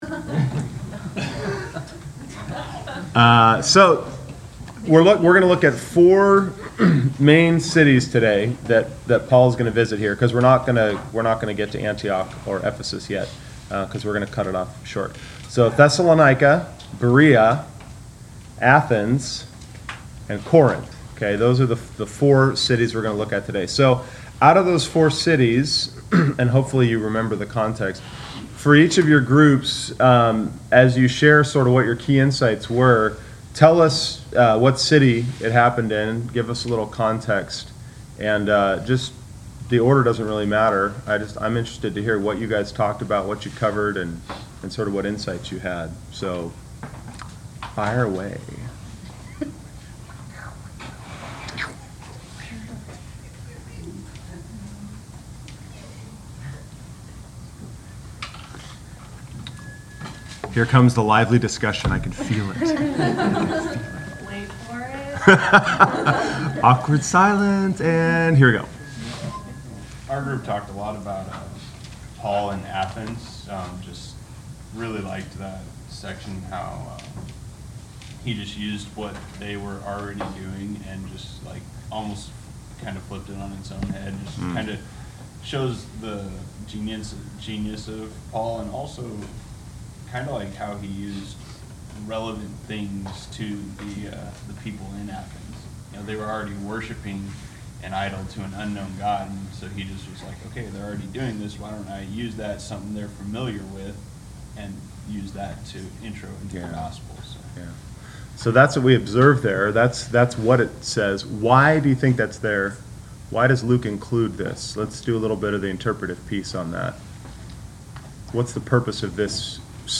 Class Session Audio December 05